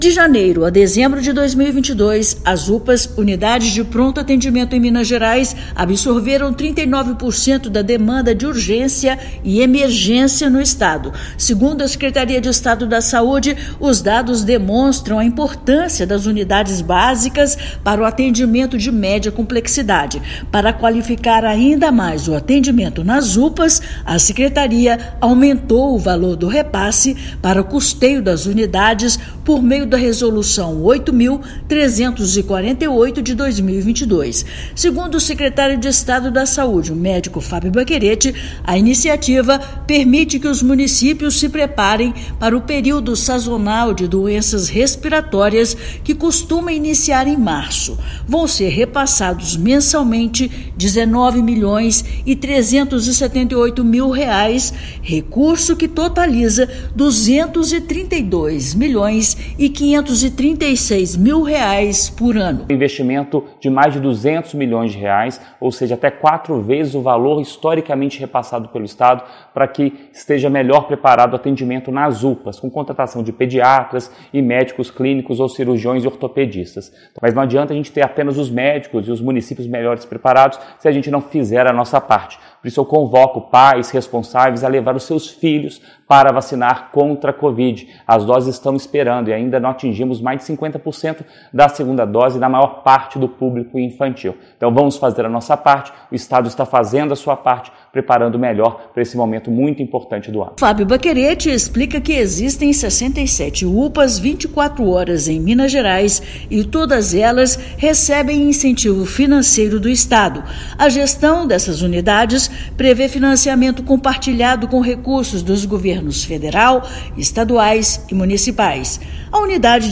[RÁDIO] Saúde estadual repassa mais de R$ 230 milhões para Unidades de Pronto Atendimento de Minas
Secretaria de Estado da Saude (SES) repassa mais de R$ 230 milhões para Unidades de Pronto Atendimento de Minas. Ouça matéria de rádio.